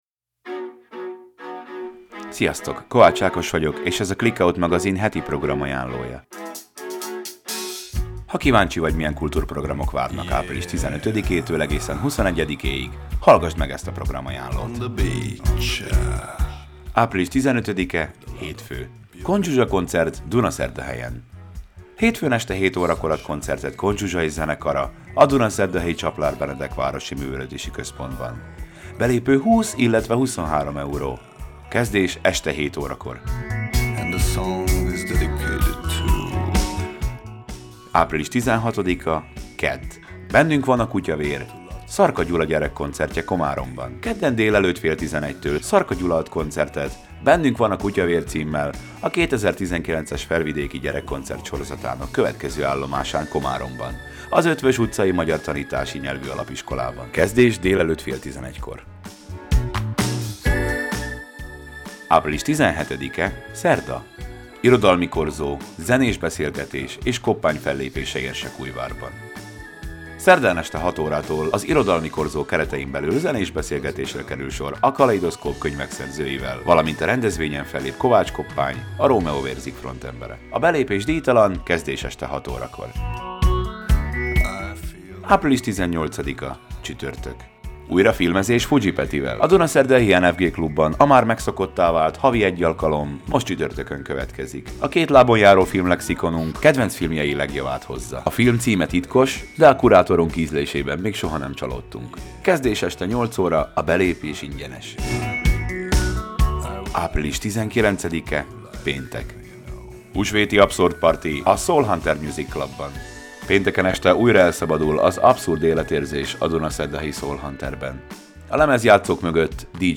A hangos programajánló célja az, hogy ne kelljen programok után keresgélned, ha pihenni és kulturálódni szeretnél! A mai podcastban a környék legtartalmasabb eseményei szerepelnek napokra bontva április 15-től egészen 21-ig.